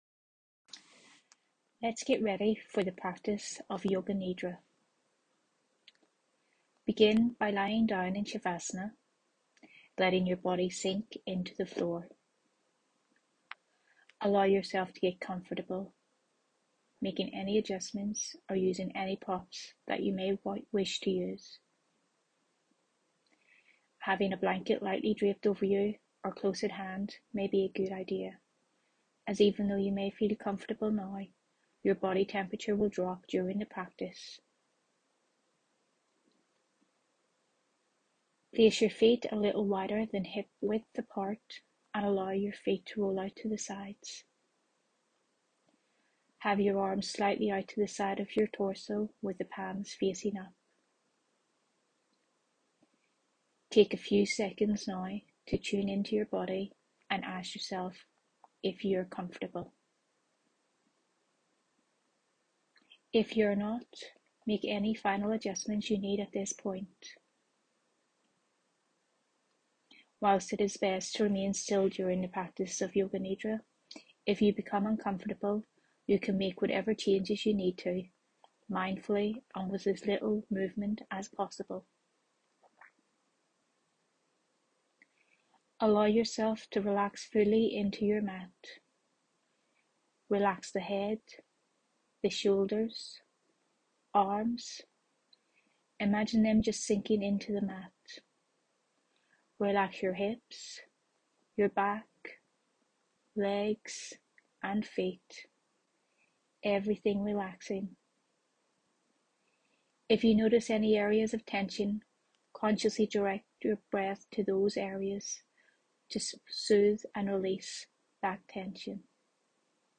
A selection of recorded yoga nidra sessions narrated by yoga teahcer